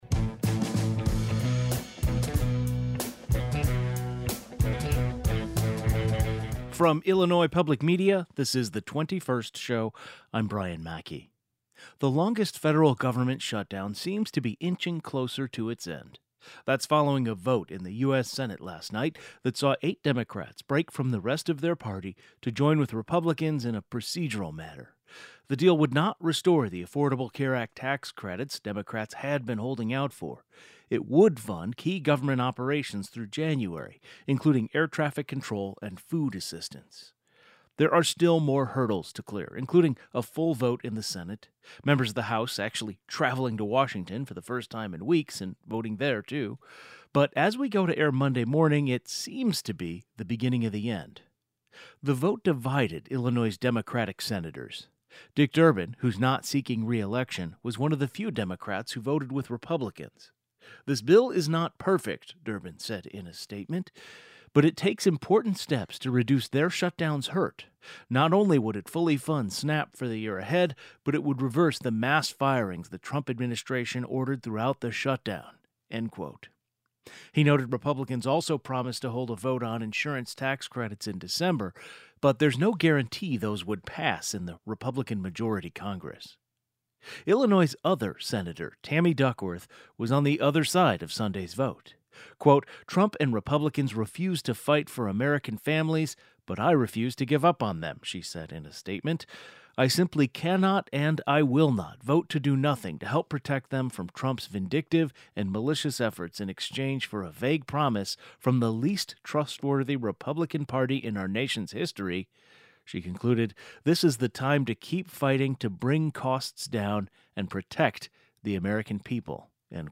The longest federal government shutdown seems to be inching closer to its end. In a previously taped interview, Senator Tammy Duckworth discusses aviation safety, SNAP, ICE agents, and what this upcoming Veteran's Day means to her. The 21st Show is Illinois' statewide weekday public radio talk show, connecting Illinois and bringing you the news, culture, and stories that matter to the 21st state.